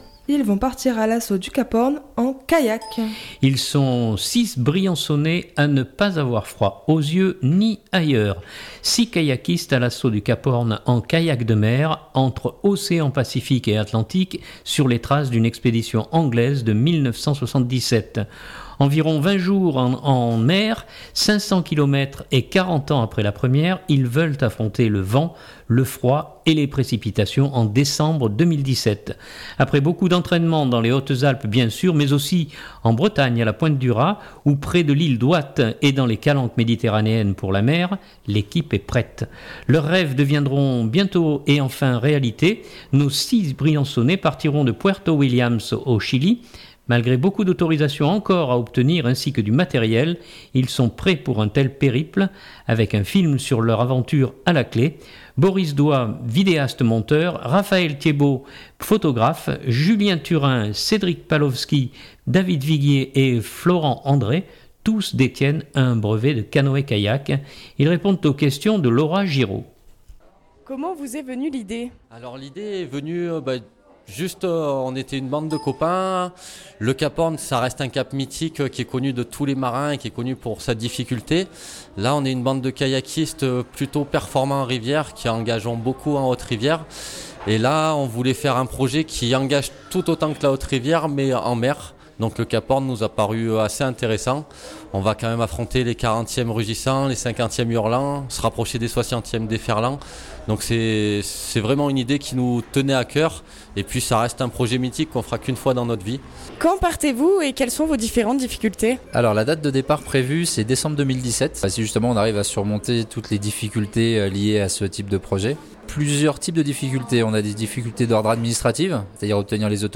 Ils répondent aux questions